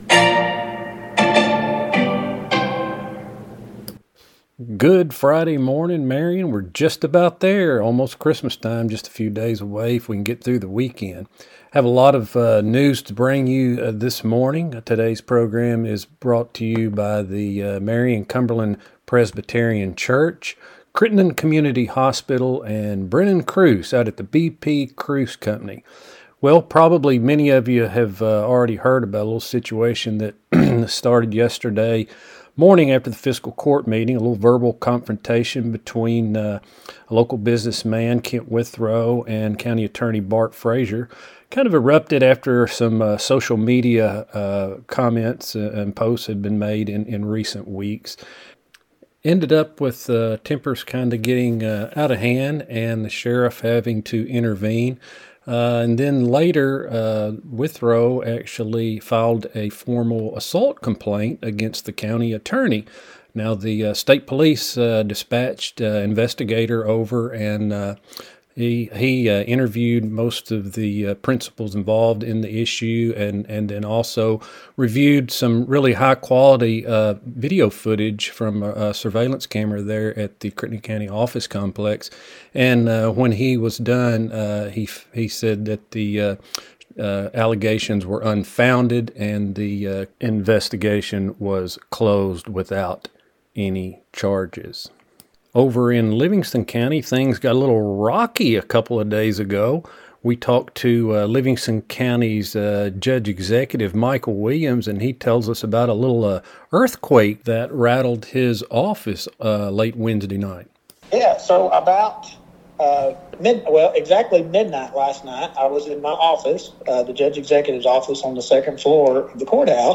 FRIDAY NEWScast | Local News on the Go